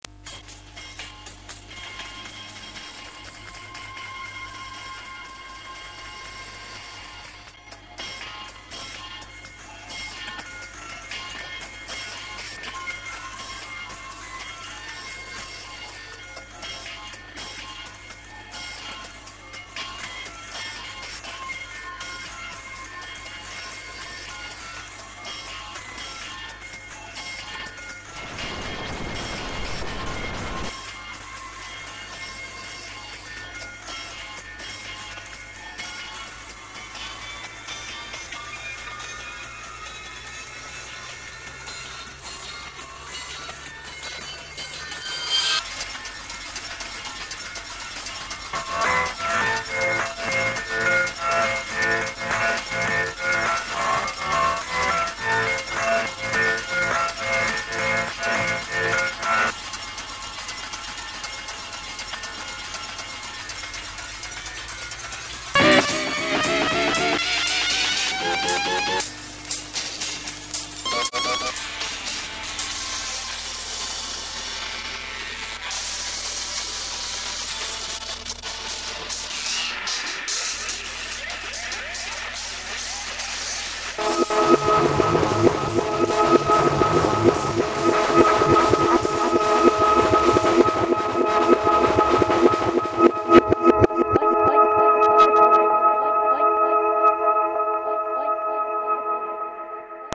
Альбом обрёл своё звучание на домашней студии